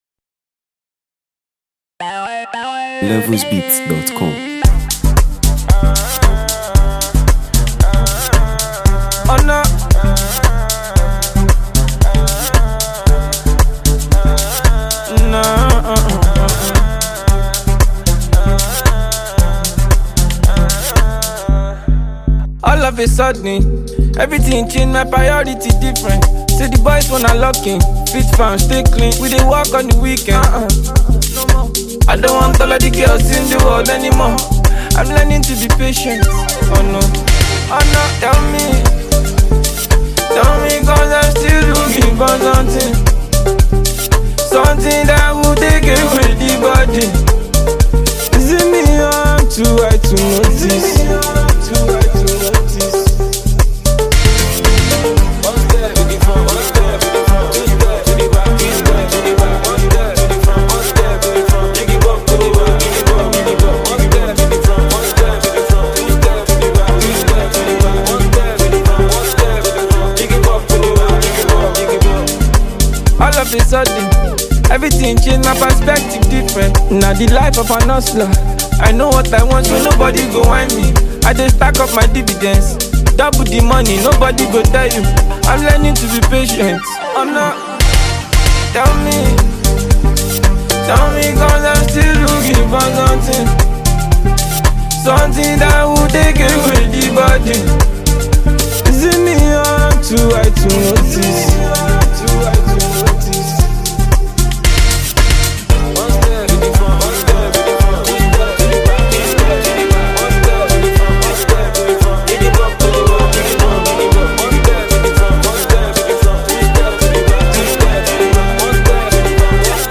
This upbeat single stands out for its simplicity and charm